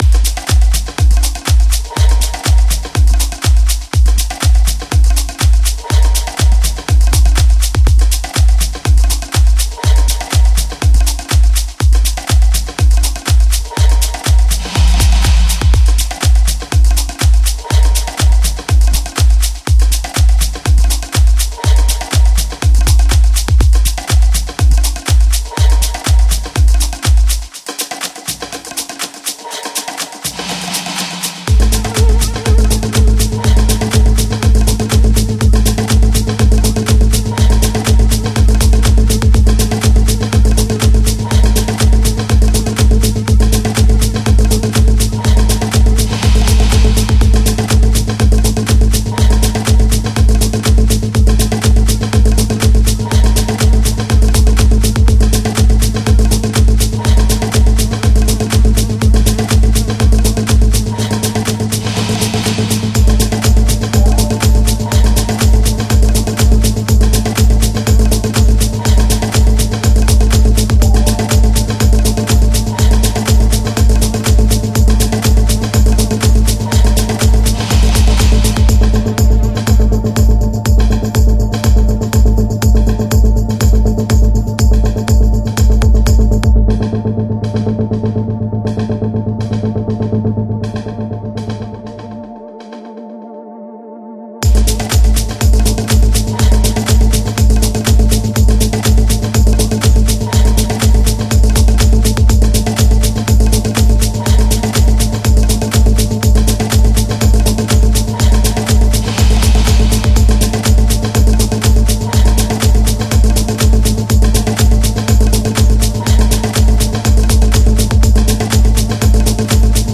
EP
Genre: Progressive House , Deep House